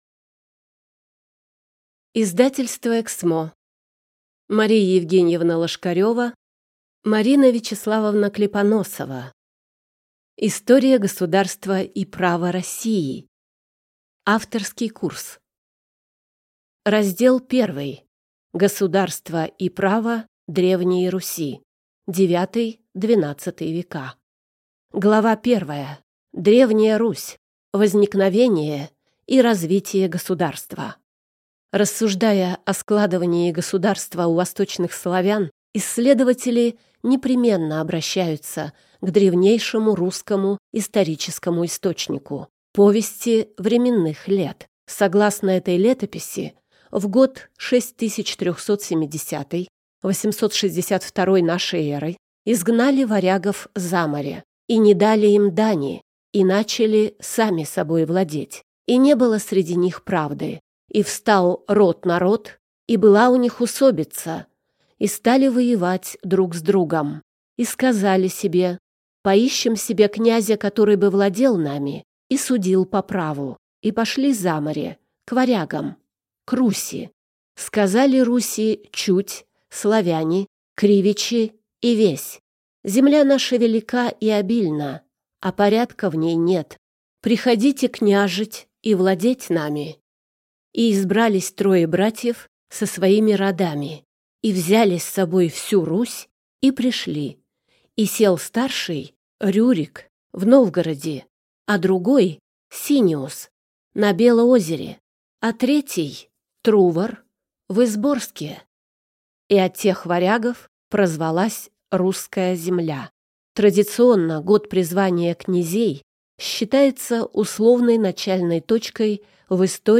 Аудиокнига История государства и права России. Авторский курс | Библиотека аудиокниг